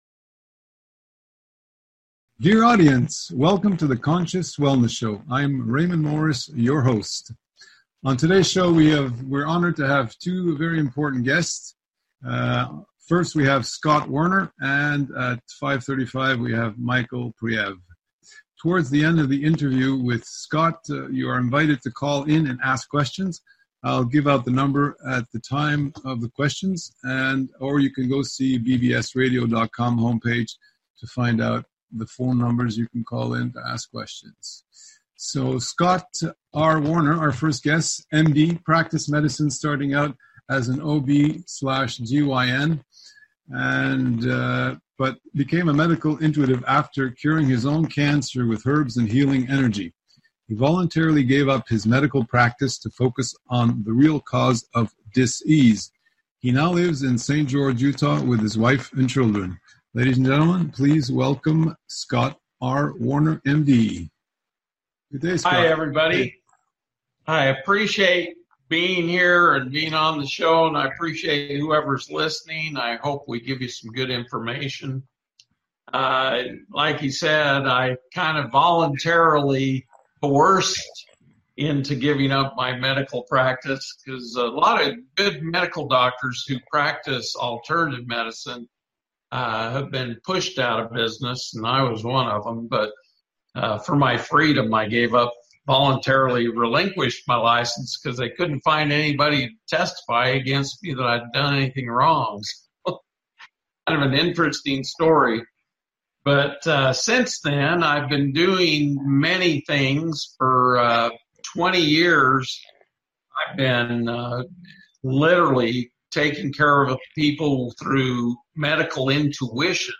5:00 pm Interview